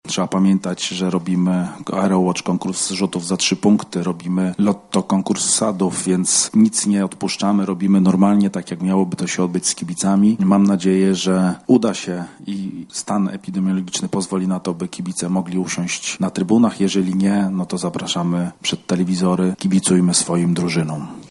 Oprócz tego zaplanowane są dodatkowe atrakcje, o których mówi Prezes Polskiego Związku Koszykówki Radosław Piesiewicz.